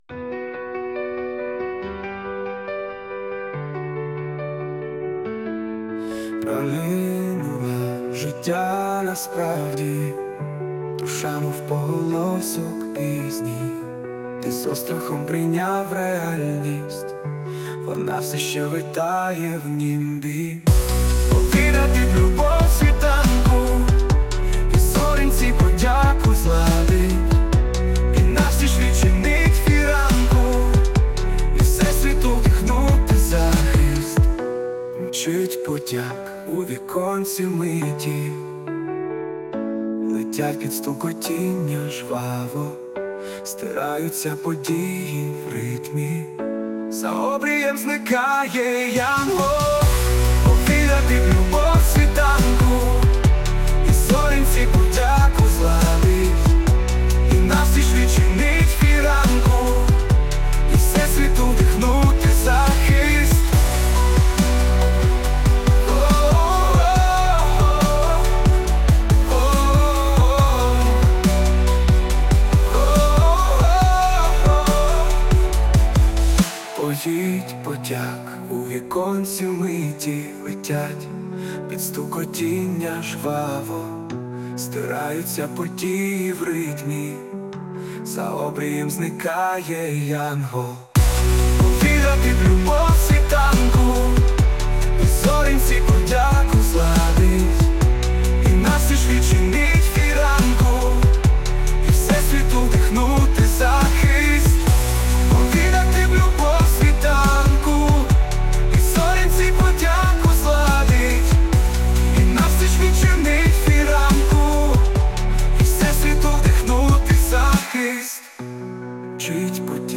Музична композиція створена за допомогою SUNO AI
СТИЛЬОВІ ЖАНРИ: Ліричний
Мелодійний твір і глибокий водночас.